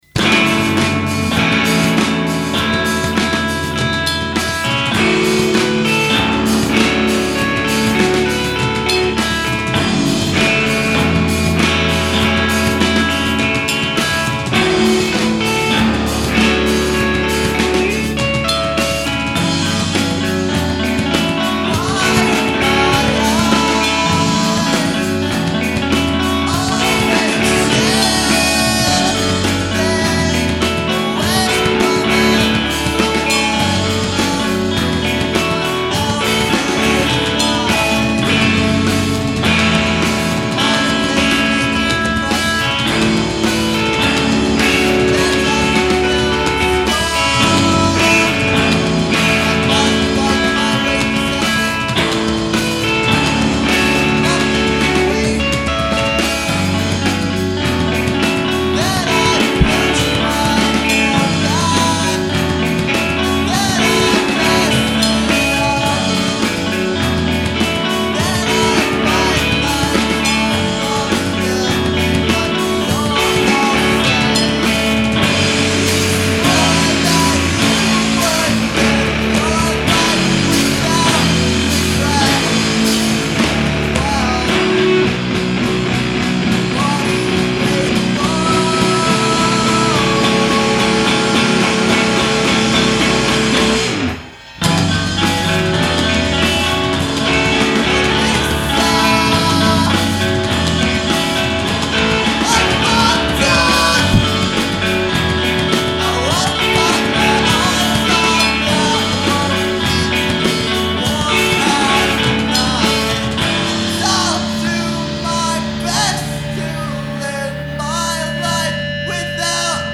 Lehigh Valley emo band
emo See all items with this value
Punk Rock Music